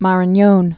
(märən-yōn, märä-nyōn)